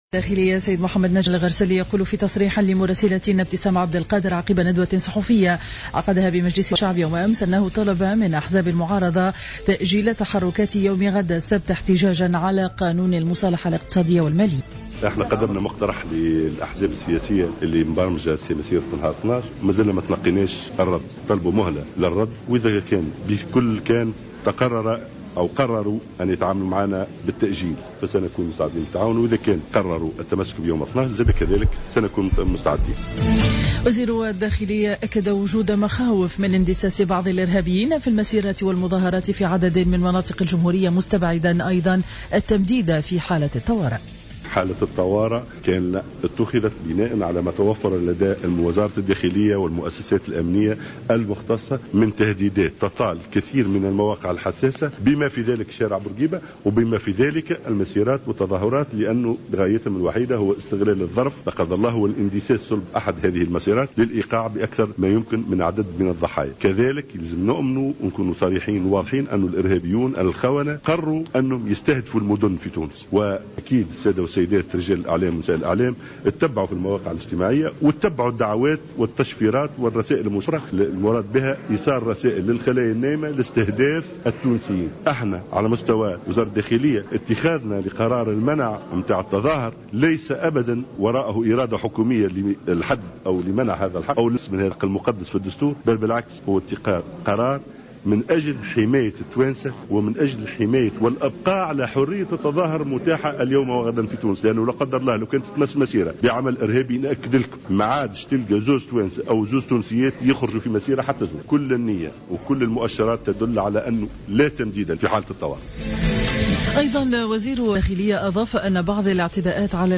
نشرة أخبار السابعة صباحا ليوم الجمعة 11 سبتمبر 2015